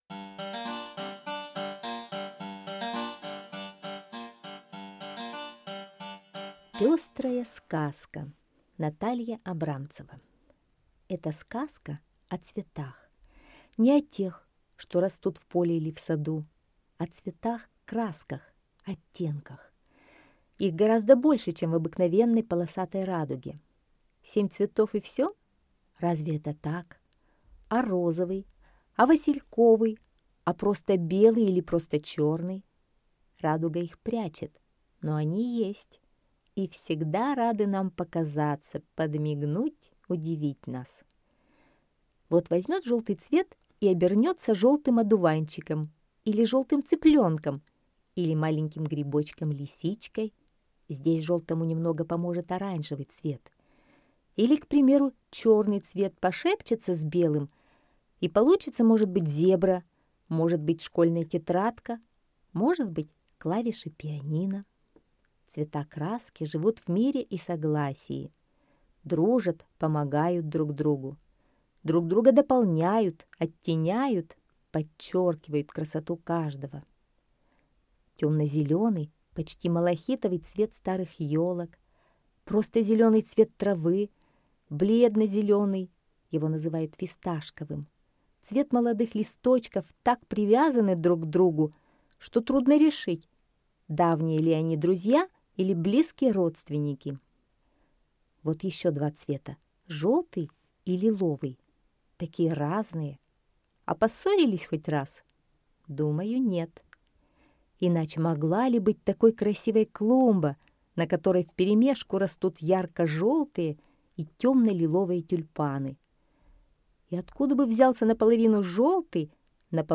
Пестрая сказка - аудиосказка Абрамцевой Н. Сказка про цвета краски, которых очень много, а не семь, как в радуге. Цвета живут дружно друг с другом.